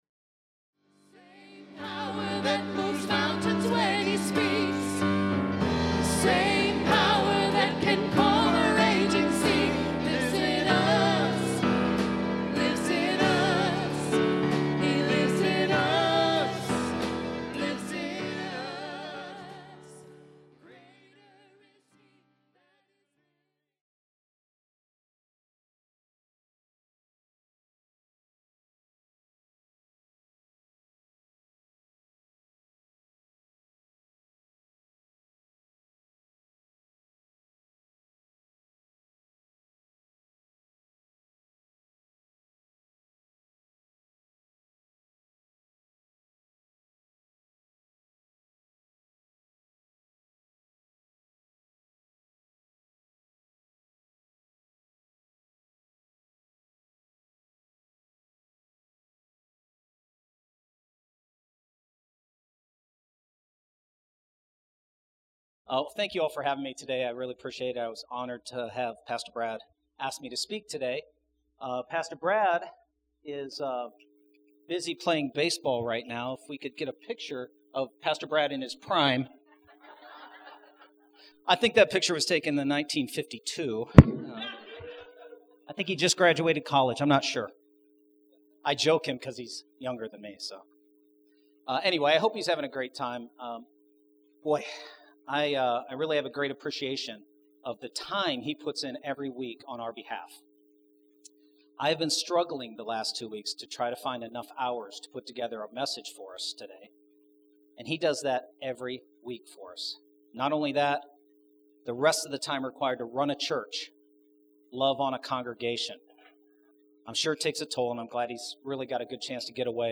Cross Pointe Church — Message